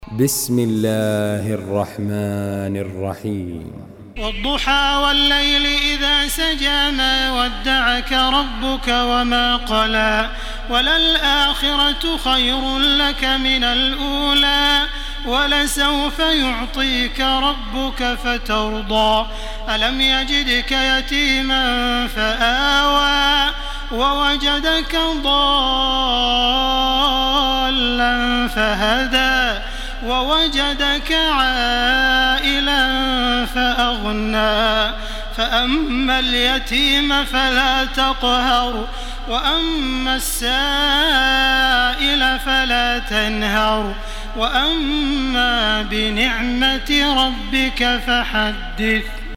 تحميل سورة الضحى بصوت تراويح الحرم المكي 1434